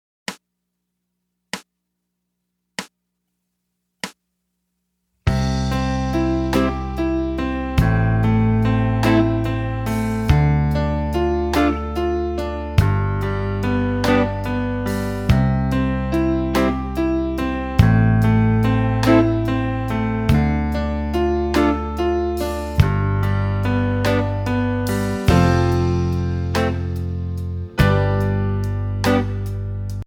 Voicing: Electric Piano